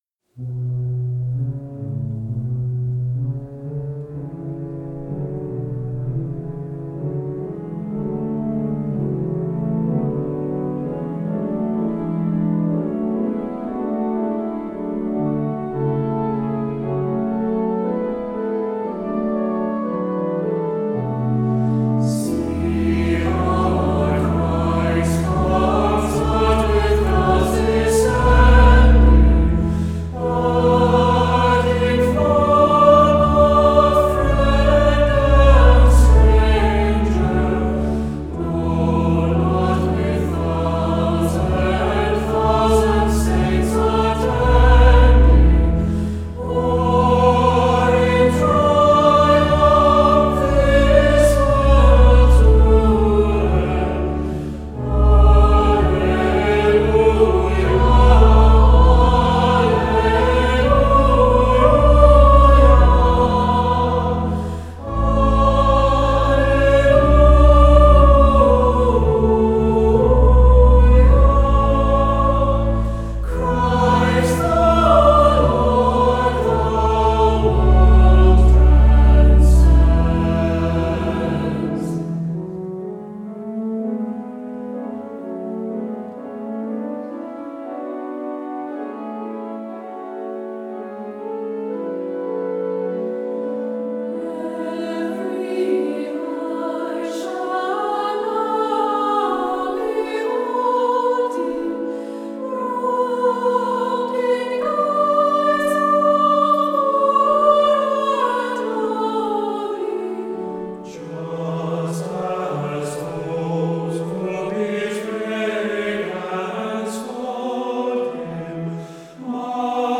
Voicing: SA(T)B/org